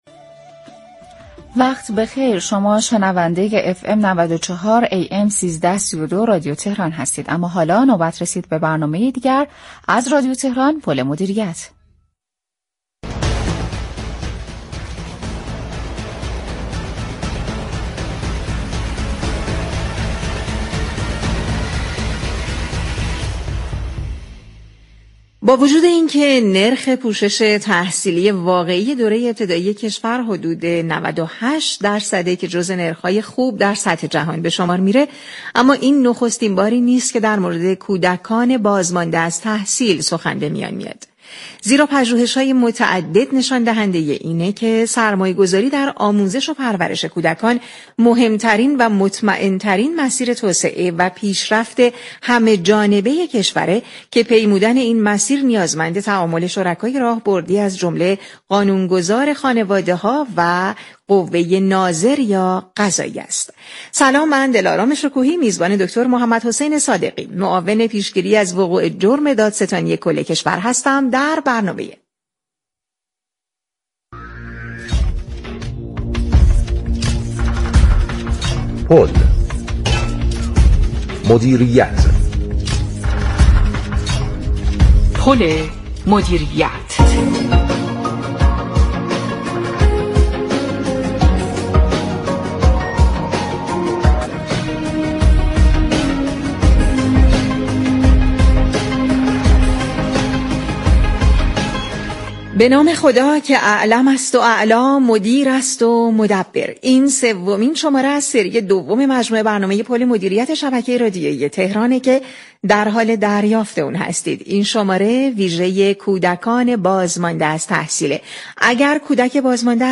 به گزارش پایگاه اطلاع رسانی رادیو تهران، محمدحسین صادقی معاون پیشگیری از وقوع جرم دادستانی كل كشور با سومین برنامه از سری دوم برنامه «پل مدیریت» كه 28 شهریورماه به مناسبت بازگشایی مدارس و آغاز سال تحصیلی جدید پخش شد در خصوص كودكان بازمانده از تحصیل گفتگو كرد.